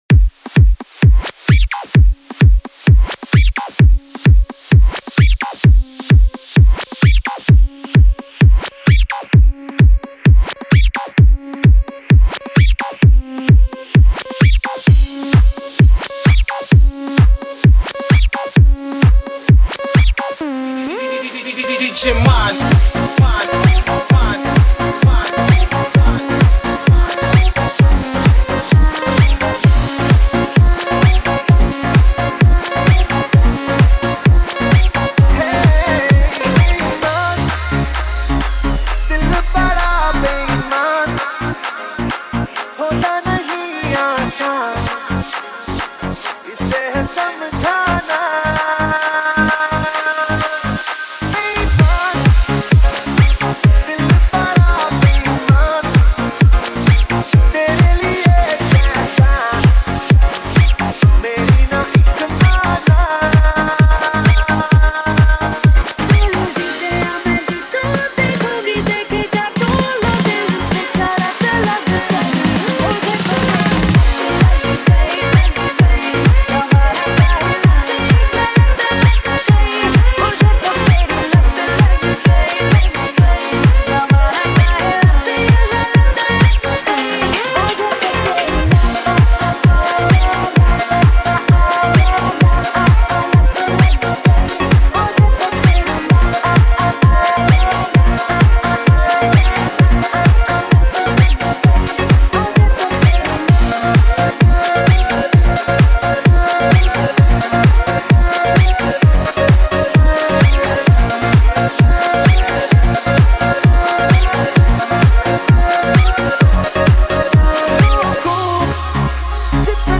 Single Dj Mixes